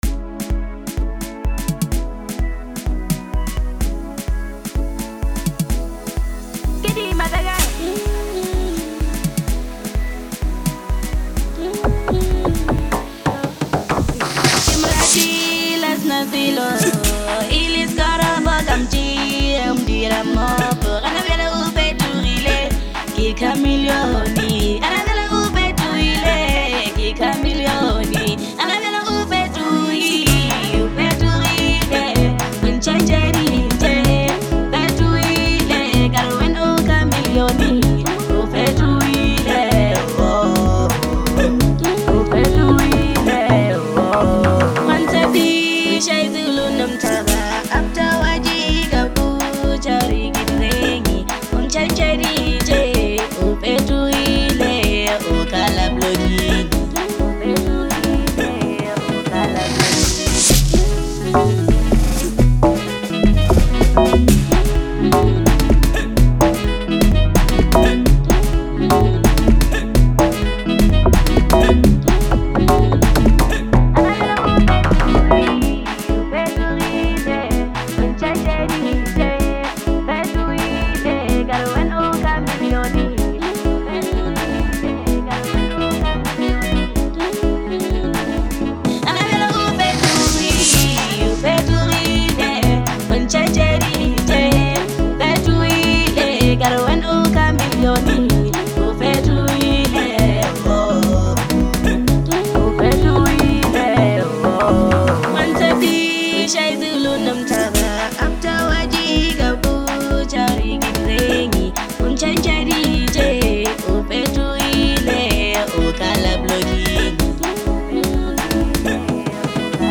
Bolo House